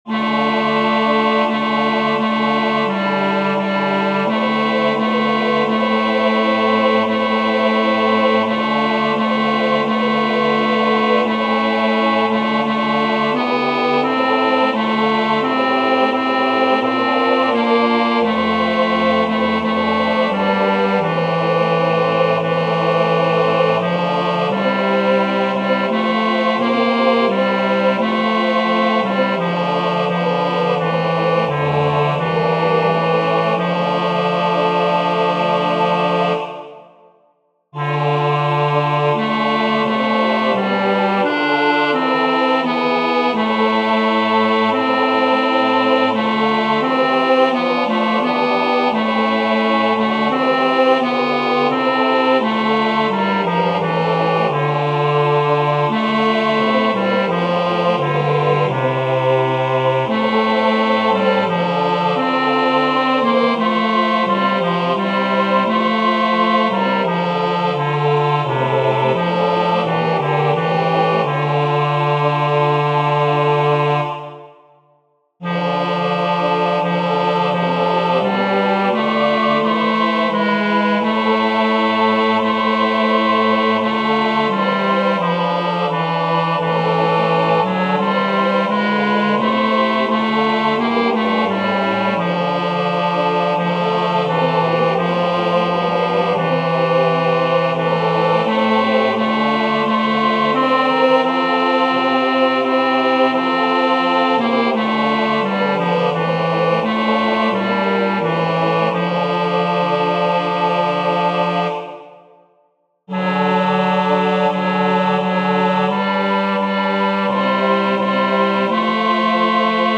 Vers 2: mf. Vanaf maat 12: f.
Tenor uitgelicht